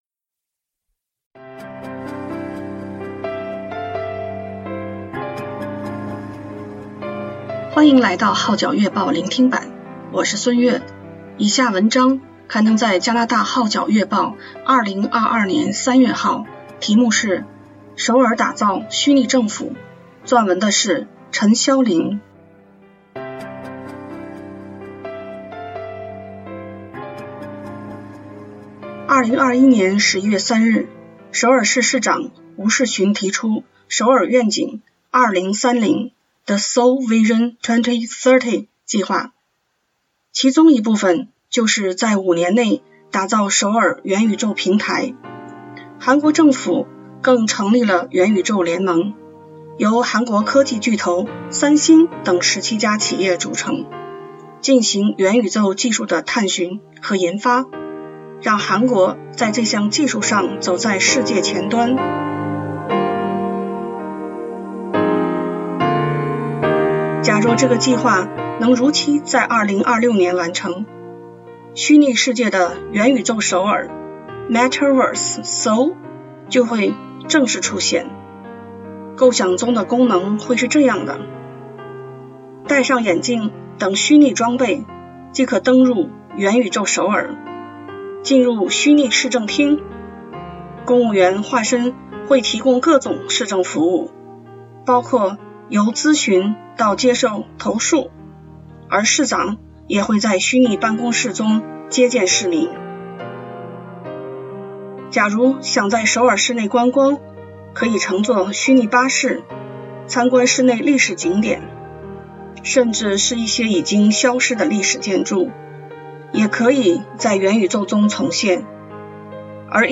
聆聽版/Audio首爾打造虛擬政府
首爾打造虛擬政府 下載粵語MP3檔案